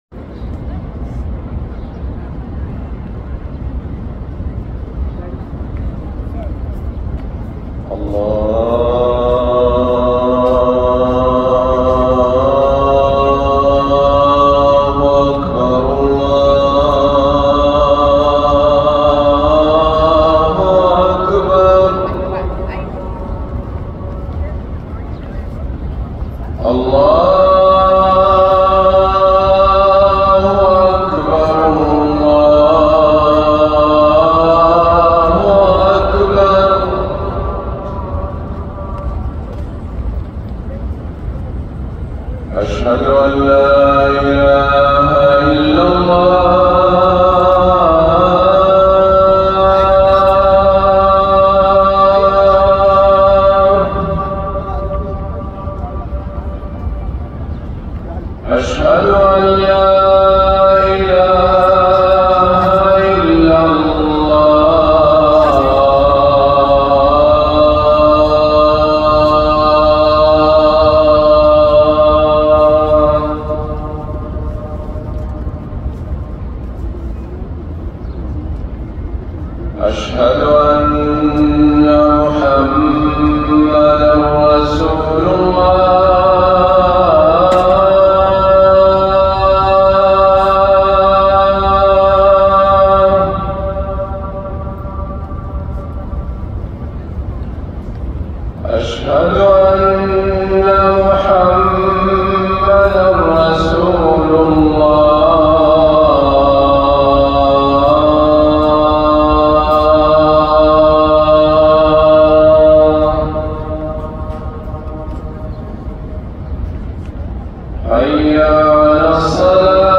الأذان الأول للفجر من الحرم النبوي